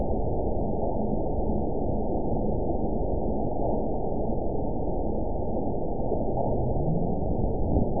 event 922423 date 12/31/24 time 21:41:34 GMT (5 months, 2 weeks ago) score 8.89 location TSS-AB02 detected by nrw target species NRW annotations +NRW Spectrogram: Frequency (kHz) vs. Time (s) audio not available .wav